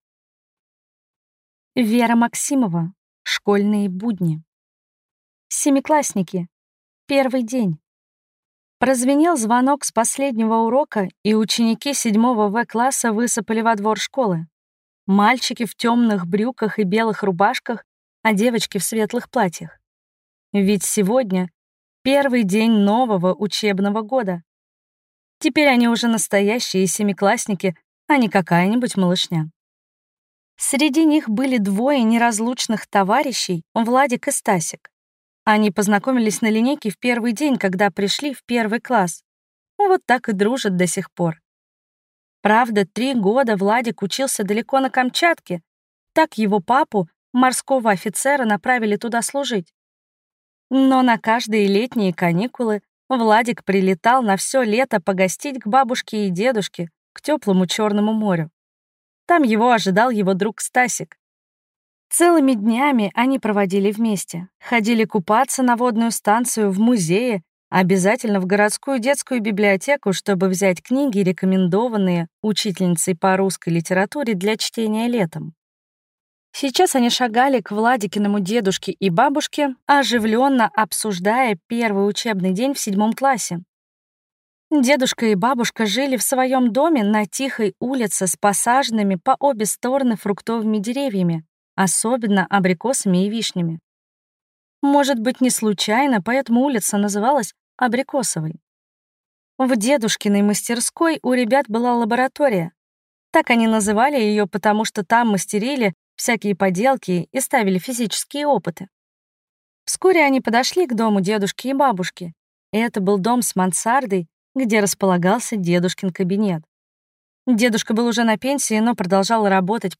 Aудиокнига Школьные будни